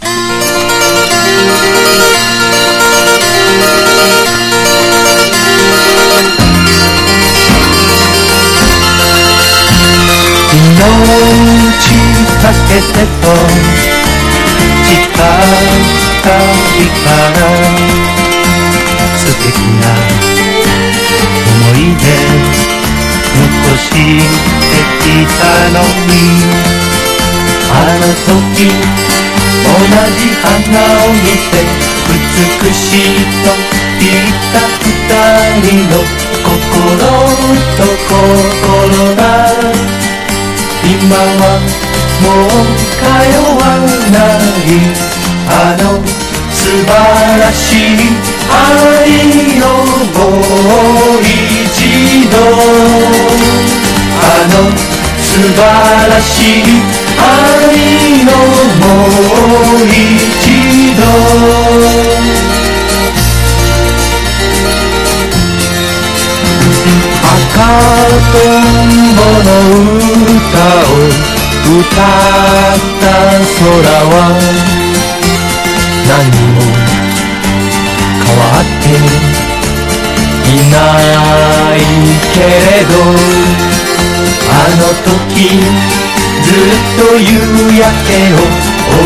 SSW / FOLK# 和モノ / ポピュラー